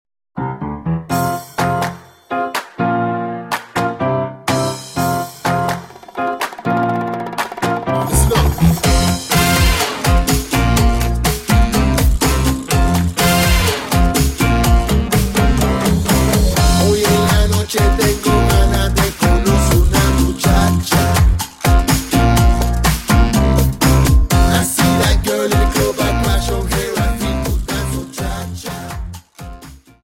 Dance: Cha Cha 31